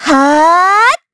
Xerah-Vox_Casting2_kr_Madness.wav